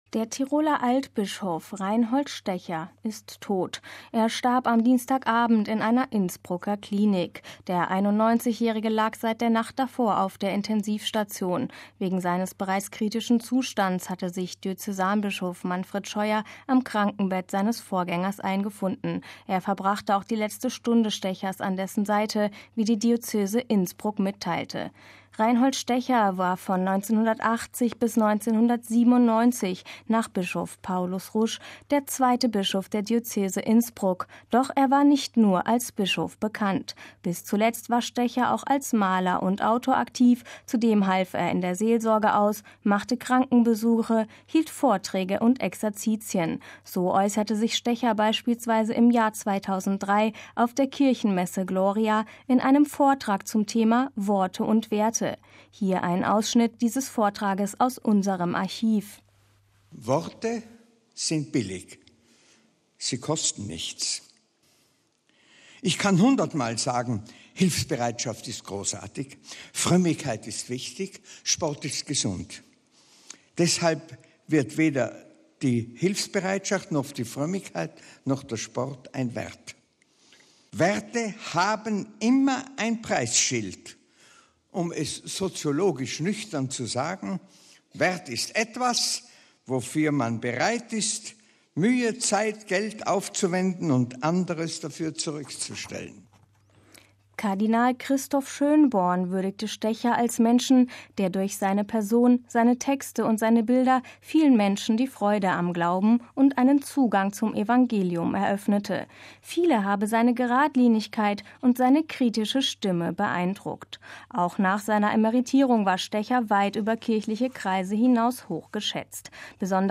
So äußerte sich Stecher beispielsweise im Jahr 2003 auf der Kirchenmesse Gloria in einem Vortrag zum Thema „Worte und Werte“. Hier ein Ausschnitt dieses Vortrages aus unserem Archiv: